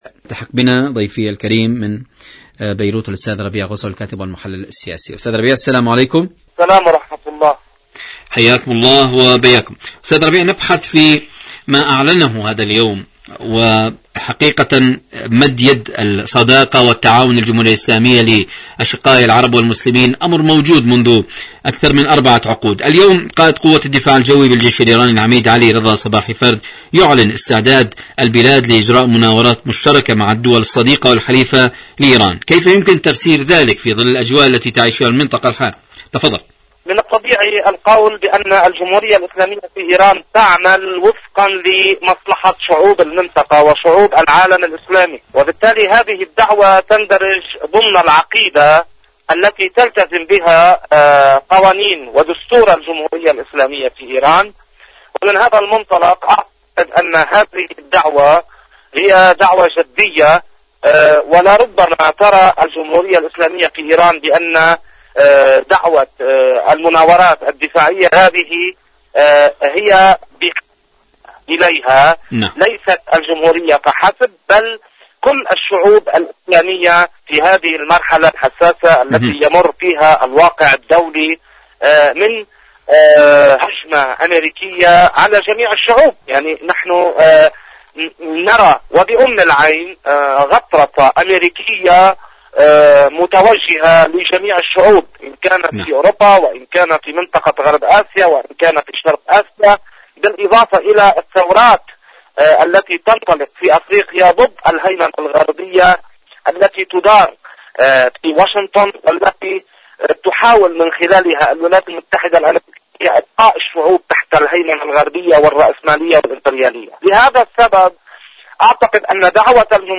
مقابلات برامج إذاعة طهران العربية مقابلات إذاعية برنامج حدث وحوار ايران المنطقة الجمهورية الإسلامية في إيران أمن المنطقة حدث وحوار شاركوا هذا الخبر مع أصدقائكم ذات صلة آليات إيران للتعامل مع الوكالة الدولية للطاقة الذرية..